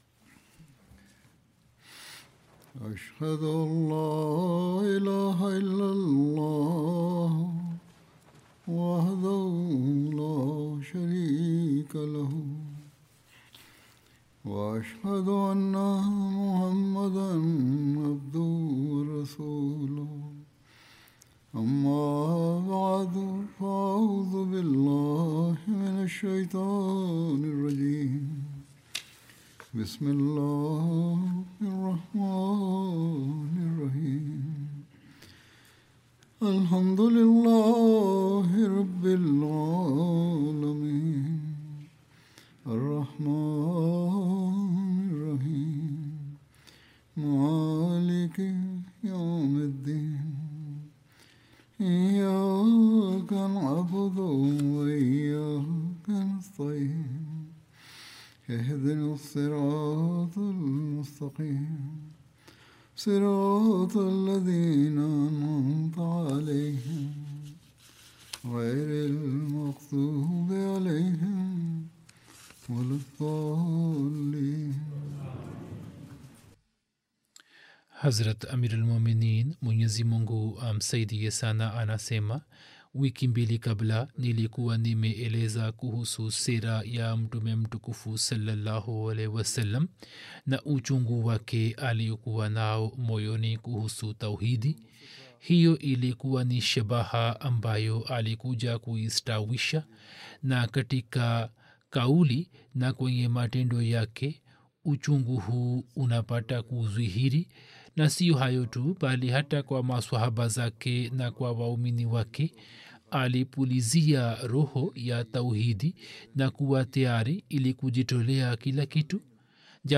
13 March 2026 The Prophet (sa) as the Guardian and Teacher of Tauheed Swahili Friday Sermon by Head of Ahmadiyya Muslim Community 50 min About Swahili translation of Friday Sermon delivered by Khalifa-tul-Masih on March 13th, 2026 (audio)